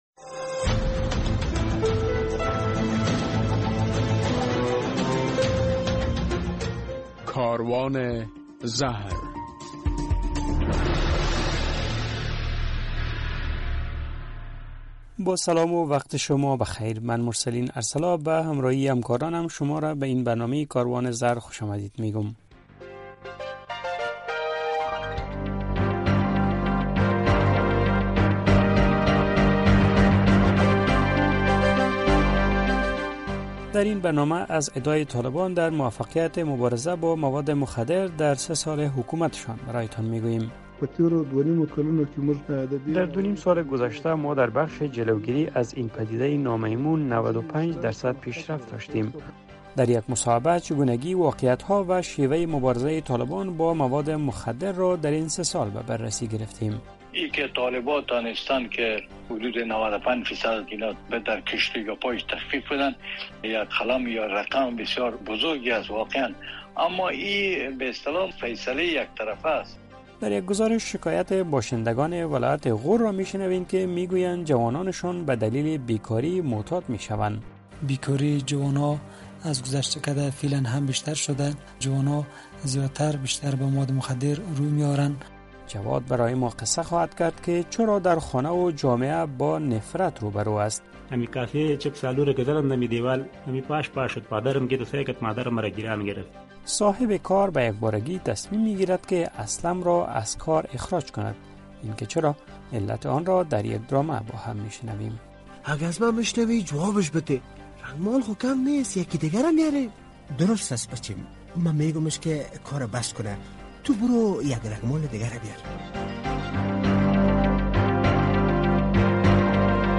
در برنامه این هفته "کاروان زهر" می‌شنوید که مقام‌های طالبان ادعا می‌کنند که در سه سال گذشته در مبارزه با مواد مخدر موفق بوده‌اند. در مصاحبه با یک تحلیل‌گر، چگونگی مبارزه دو و نیم سال گذشته طالبان با مواد مخدر را به بررسی گرفتیم.